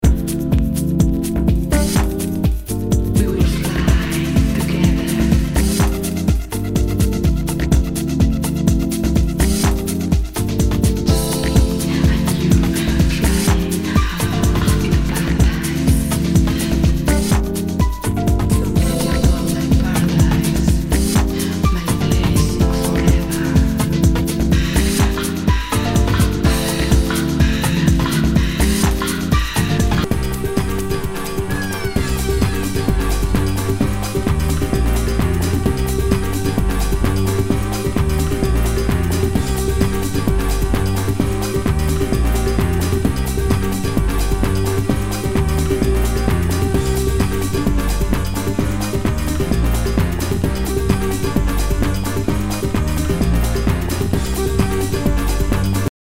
HOUSE/TECHNO/ELECTRO